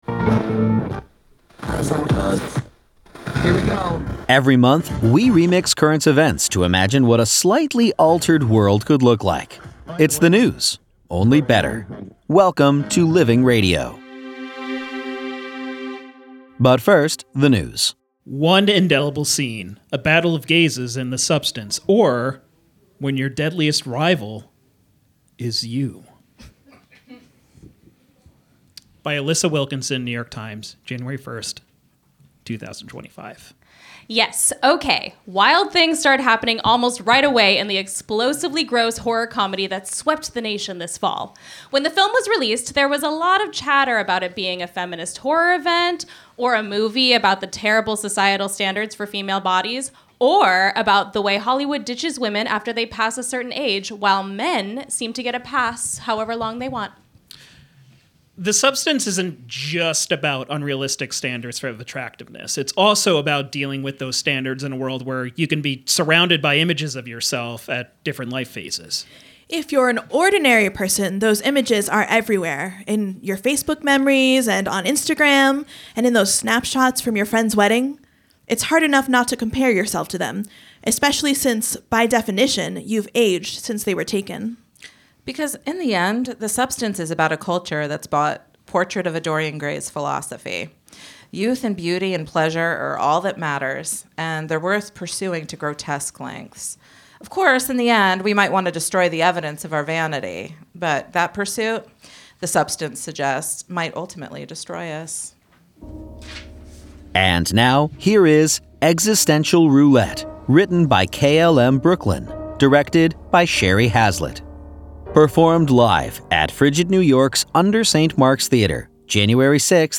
performed live at UNDER St. Mark’s Theater, January 6, 2025